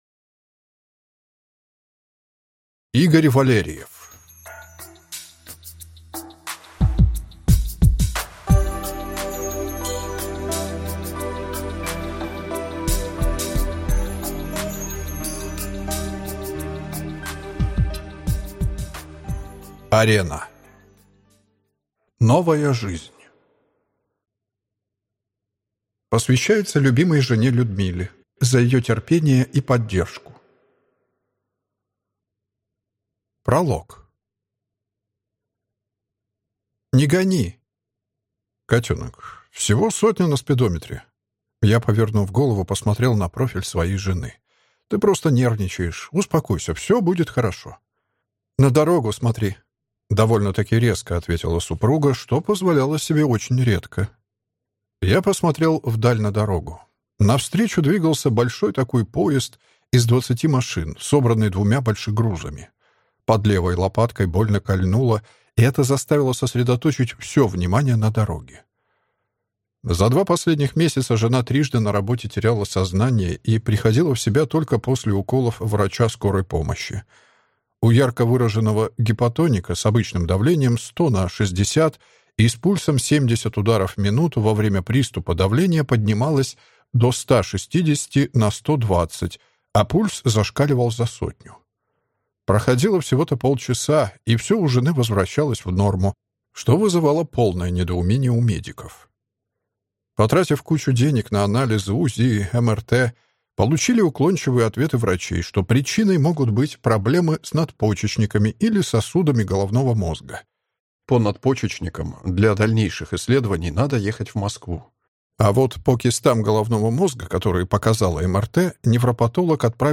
Аудиокнига Арена. Новая жизнь | Библиотека аудиокниг